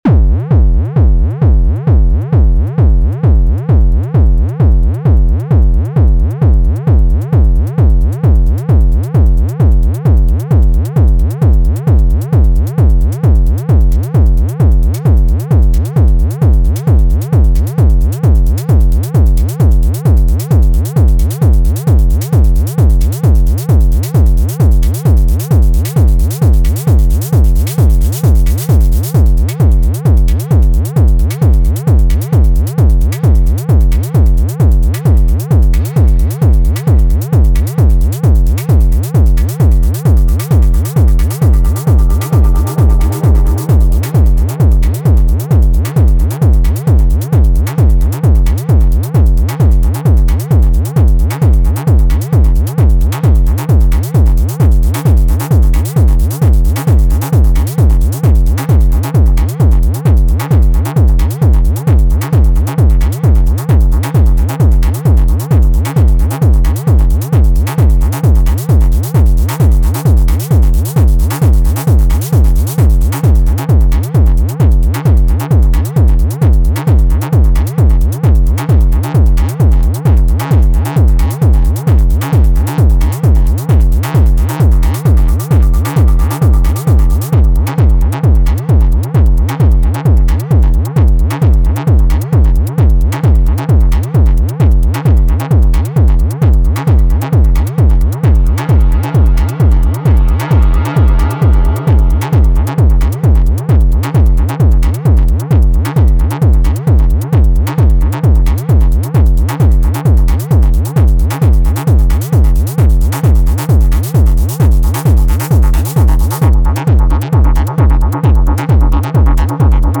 Here’s a little jam I did with Arturia MS-20 V, I thought the pitch envelope after the kick had a similar sonic feel to the sound he uses (otherwise this is nothing like his stuff but I feel you could get close with the MS-20!)